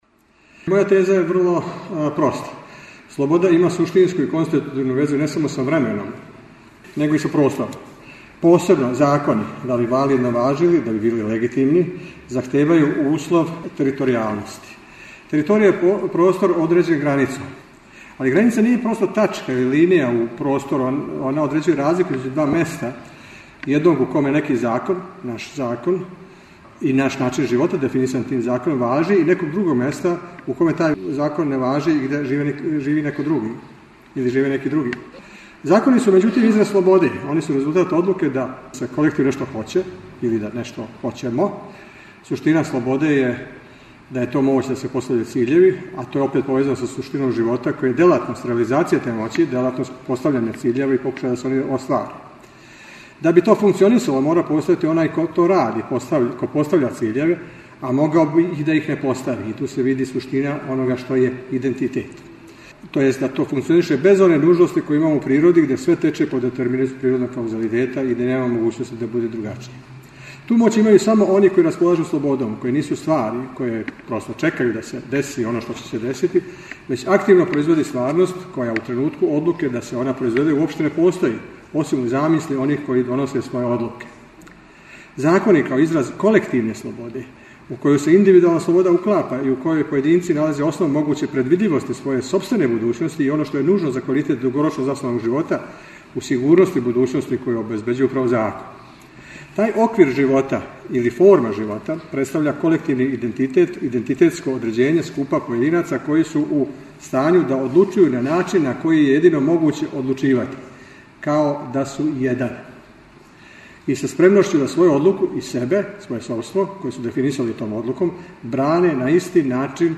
У оквиру Видовданских свечаности града Крушевца 17. и 18. јуна у Културном центру Крушевац одржана је 27. Крушевачка филозофско-књижевна школа.